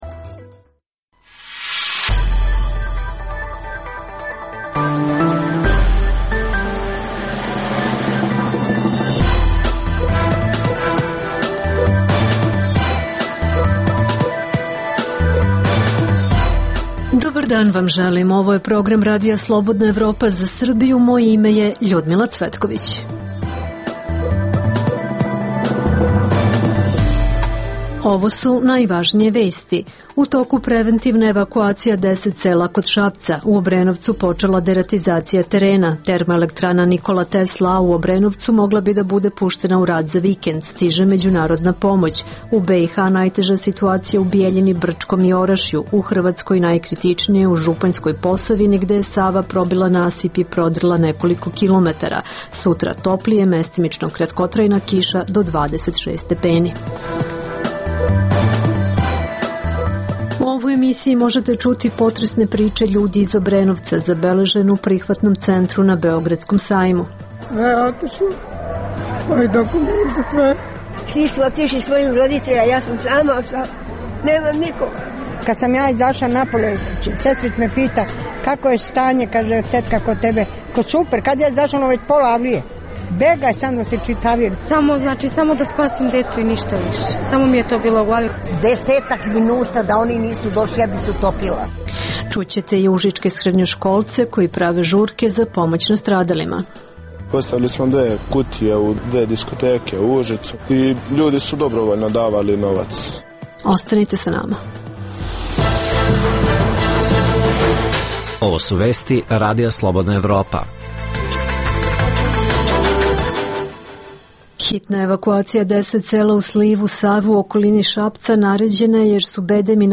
Naši reporteri izveštavaju sa kriznih područja, iz sela u slivu Save u okolini Šapca gde je naređena evakuacija jer su bedemi natopljeni vodom i iz sremskog sela Jamena u šidskoj opštini koje je pod vodom. Izveštavamo o klizištima koja su krenula u Valjevu i Bajinoj bašti. Možete čuti potresne priče ljudi iz Obrenovca, zabeležene u jednom od prihvatnih centara.